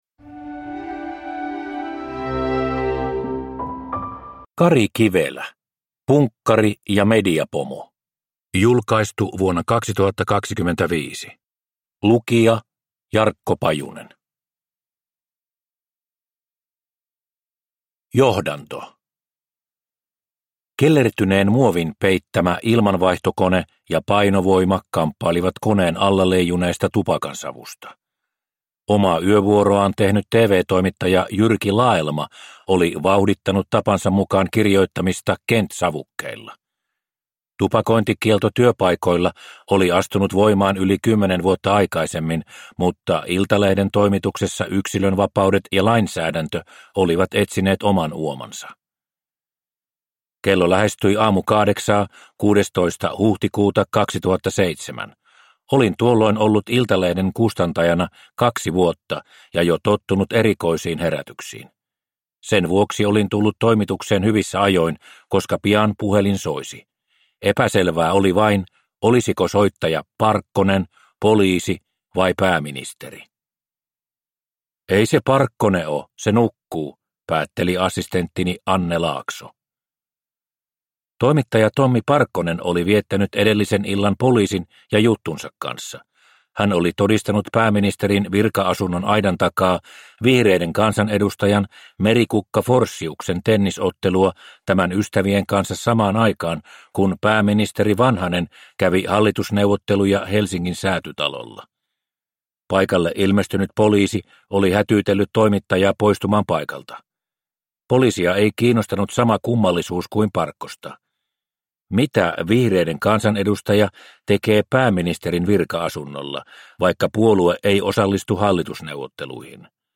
Punkkari ja mediapomo – Ljudbok